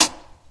Gunshots
syringeproj.ogg